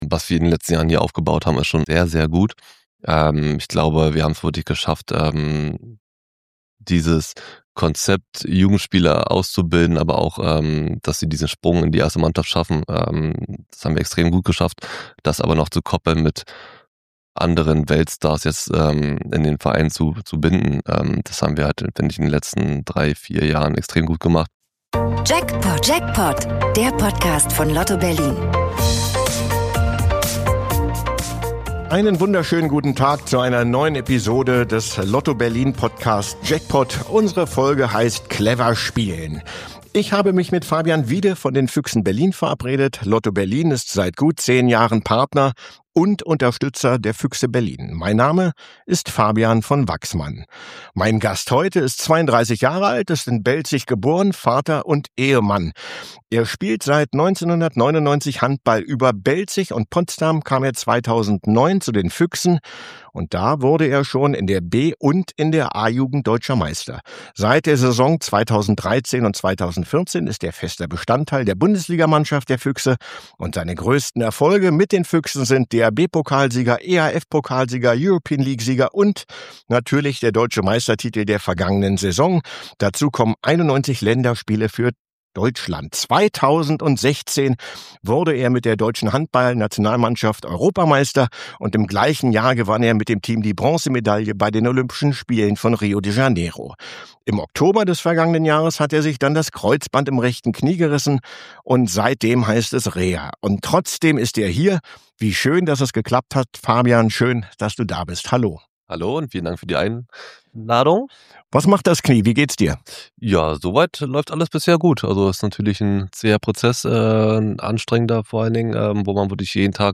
Inside Füchse Berlin - Interview mit Fabian Wiede ~ Jackpot Podcast